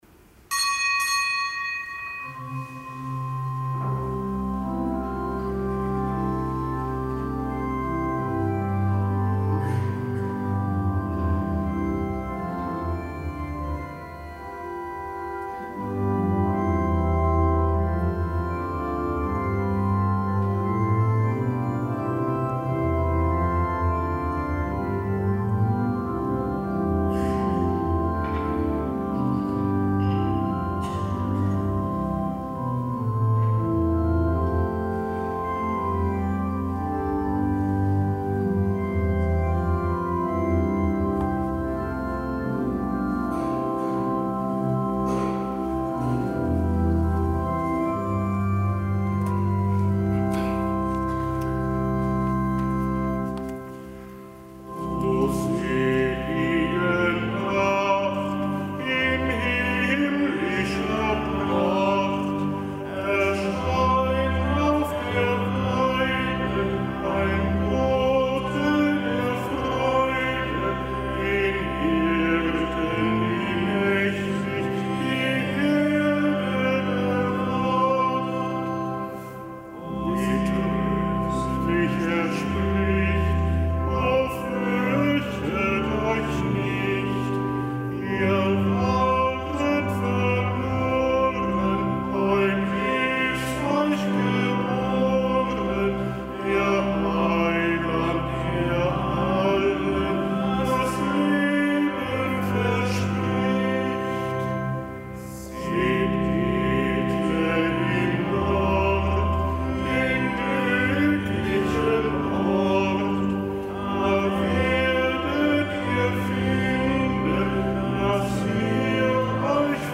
Kapitelsmesse am sechsten Tag der Weihnachtsoktav
Kapitelsmesse aus dem Kölner Dom am sechsten Tag der Weihnachtsoktav.